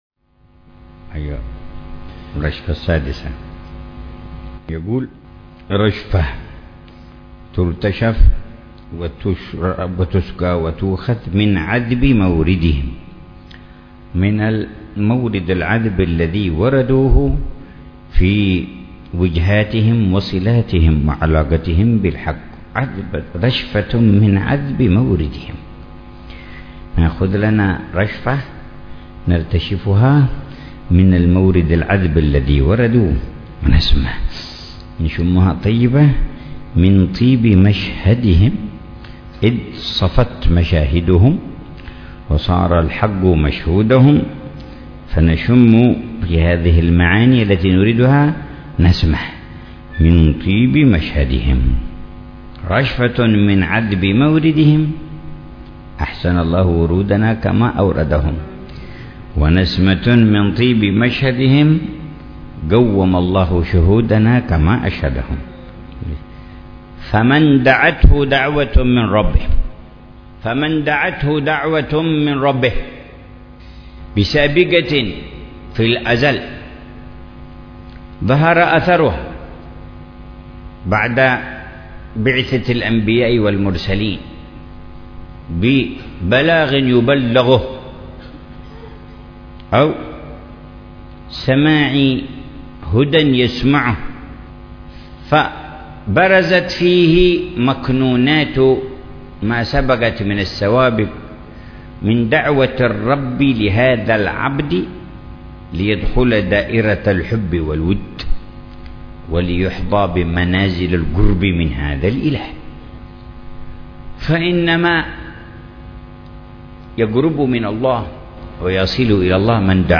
شرح الحبيب عمر بن حفيظ لرشفات أهل الكمال ونسمات أهل الوصال.